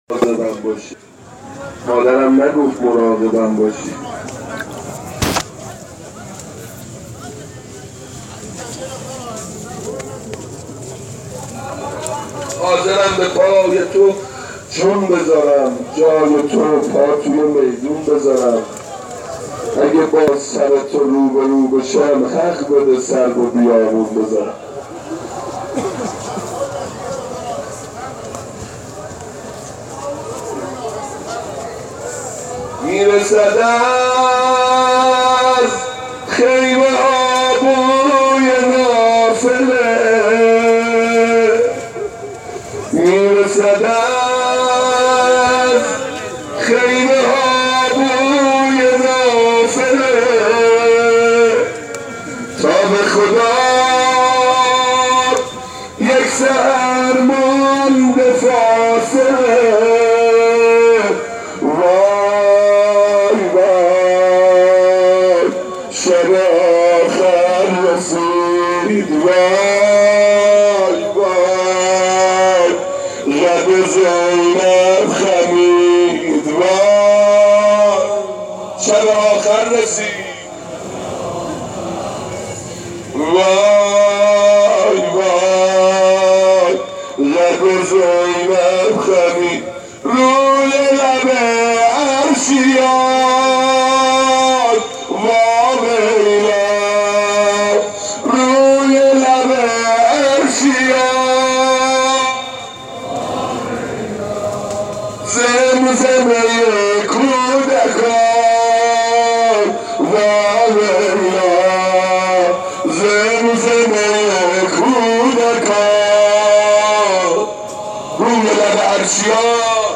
طرف دستگاه ضبط و شایدم موبایلشو دستش گرفته صدارو ضبط کرده صدای خودشم توش هست از گریه تا بینی بالاکشیدن